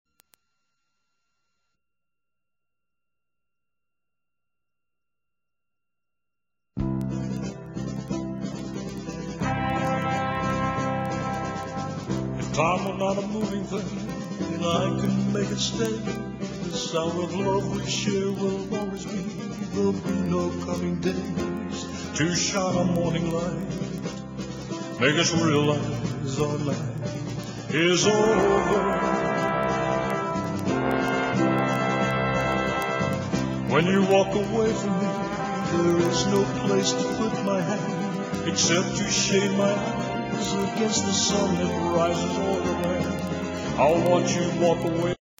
w/vocal